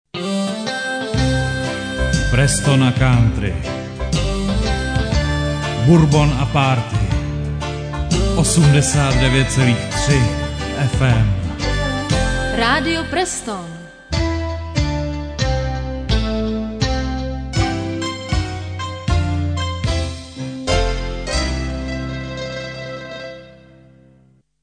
Jingle...